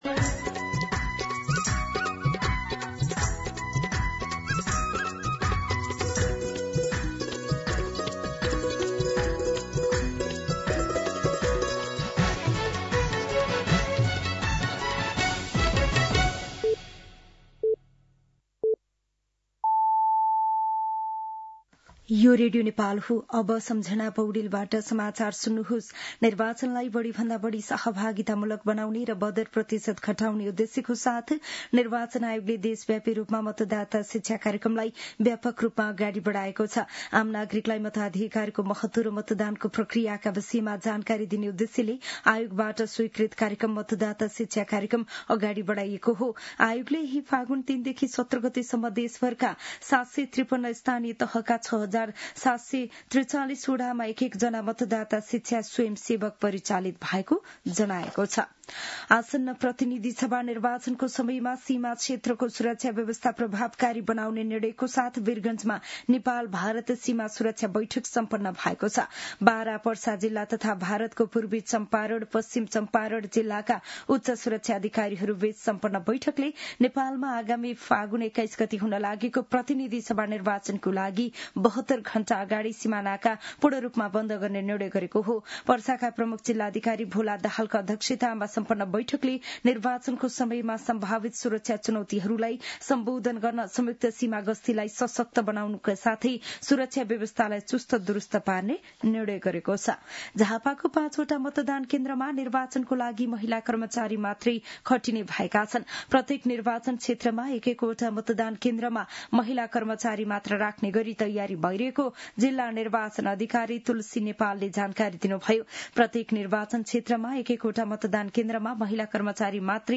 An online outlet of Nepal's national radio broadcaster
दिउँसो १ बजेको नेपाली समाचार : १० फागुन , २०८२
1-pm-Nepali-News-5.mp3